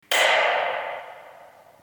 Sound Effects
Loud Lightswitch